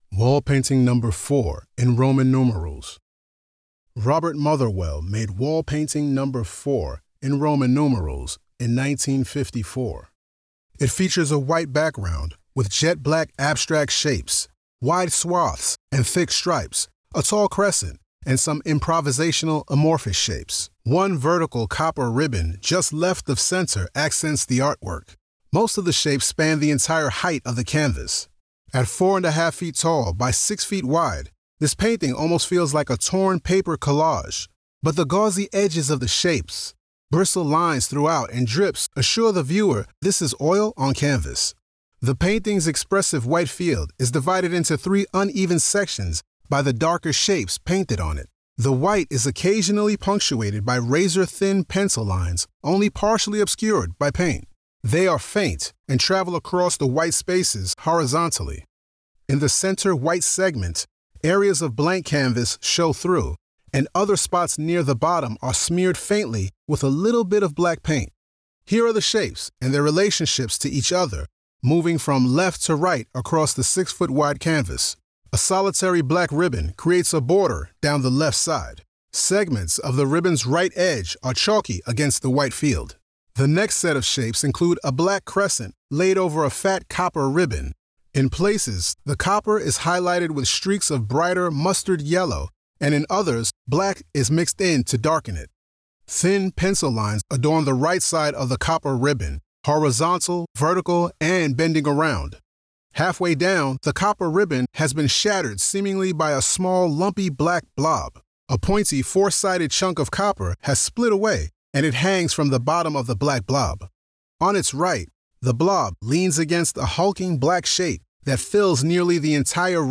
Audio Description (02:31)